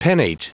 Prononciation du mot pennate en anglais (fichier audio)
Prononciation du mot : pennate